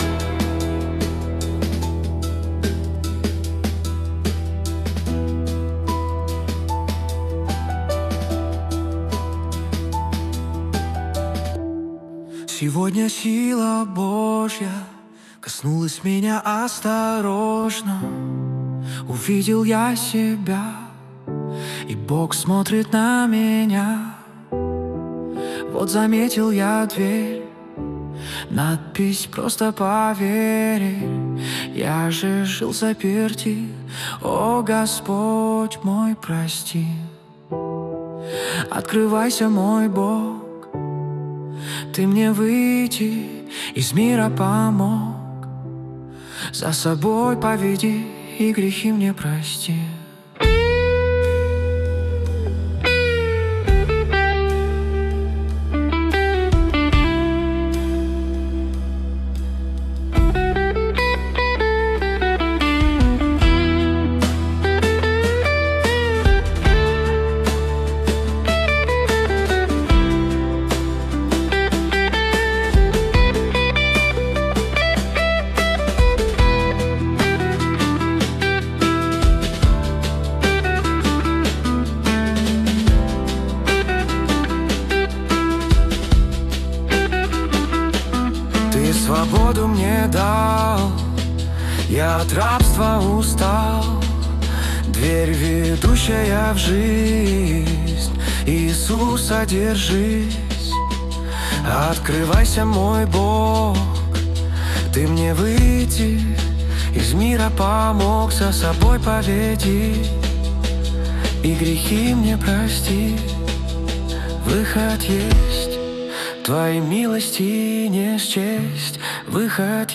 песня ai
123 просмотра 713 прослушиваний 47 скачиваний BPM: 75